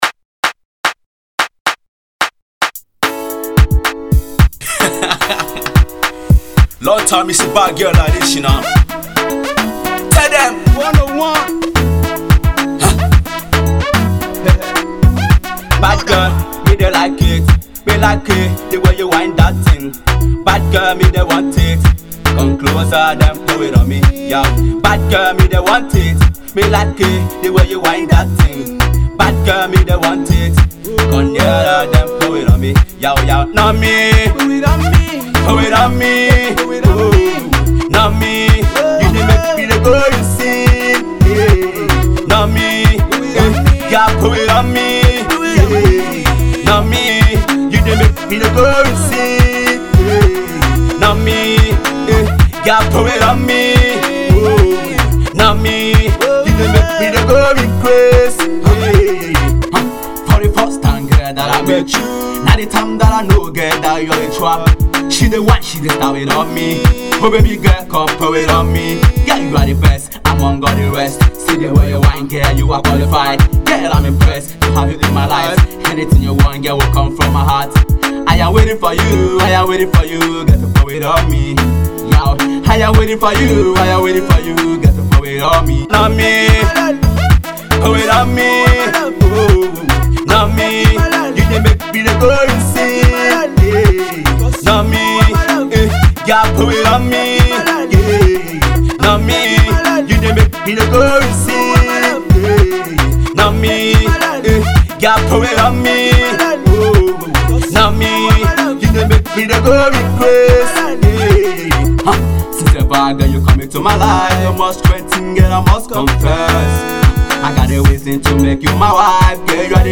Naija Music
Ghana based Nigerian music duo
This song will get you dancing!!!